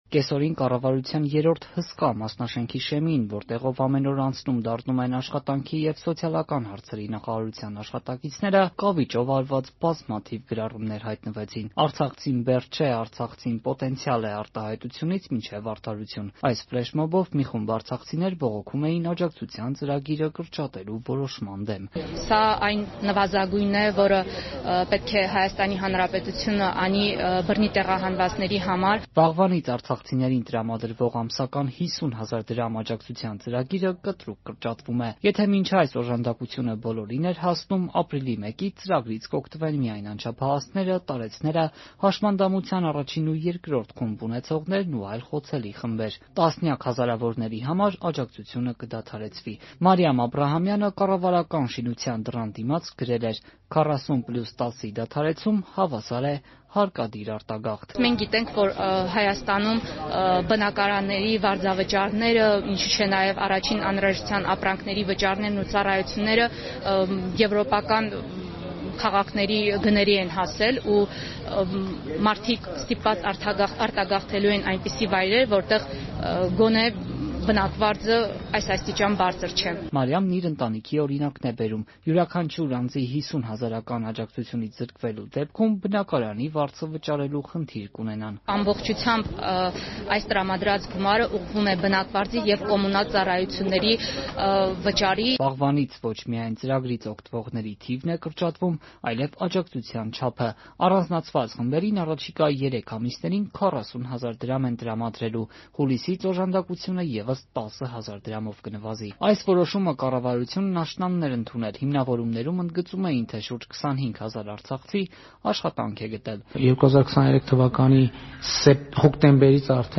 «Արցախցին բեռ չէ», «արդարություն». ակցիա կառավարության մասնաշենքի մոտ
Ռեպորտաժներ